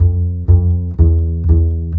Rock-Pop 11 Bass 02.wav